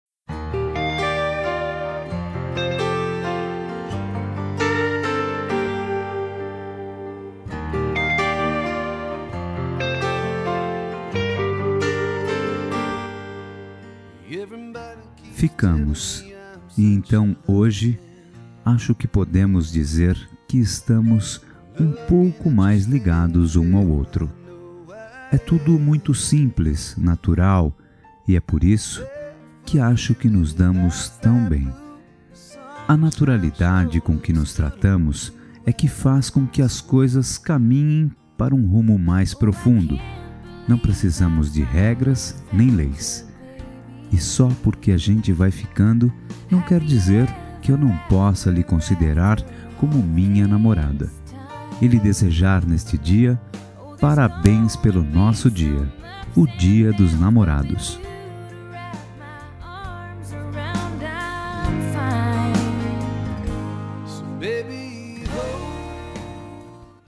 Telemensagem Dia Dos Namorados Ficante
Voz Masculina